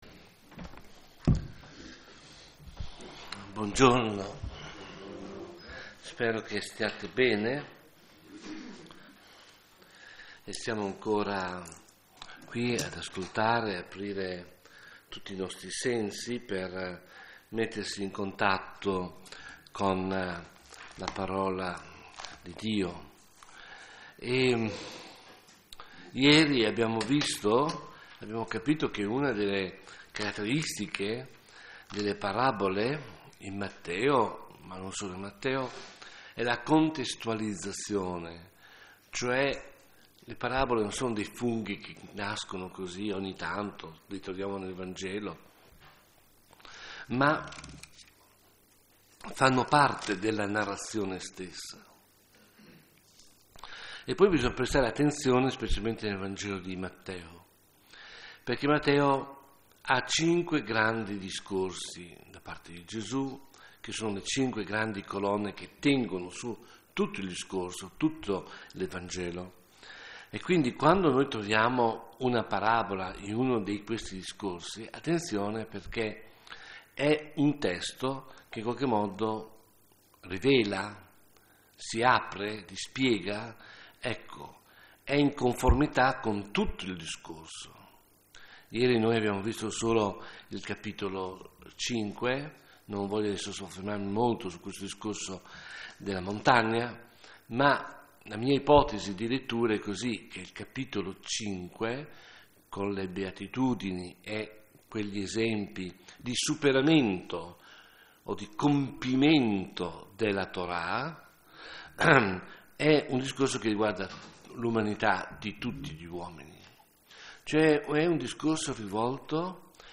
Serie: Meditazione
qui a Villa Immacolata